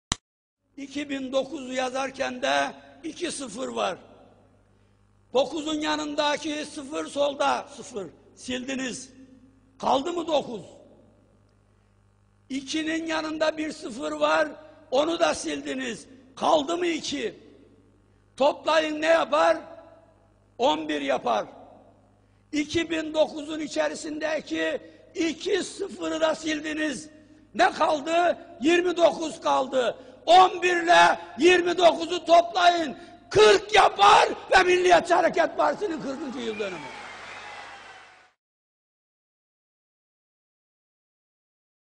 Devlet Bahçeli’nin 2009 yılında bir parti toplantısında, partisinin 40. yılına atıfta bulunurken yaptığı iddia edilen bir matematiksel hesaplamaya dayanan ve sonrasında popüler kültürde yer edinen bir ifadedir.